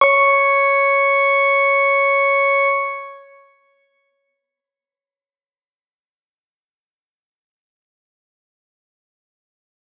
X_Grain-C#5-pp.wav